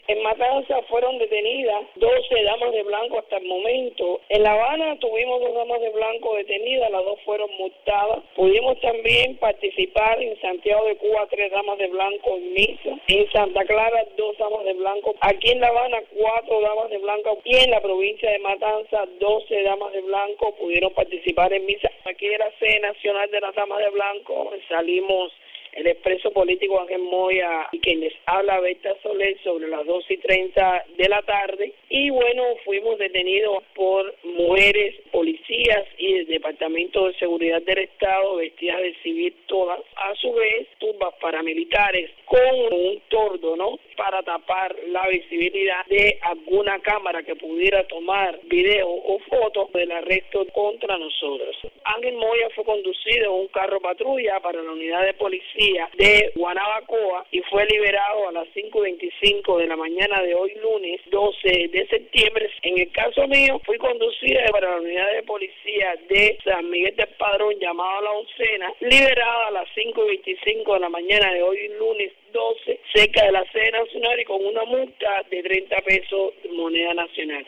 Declaraciones de Berta Soler a Radio Martí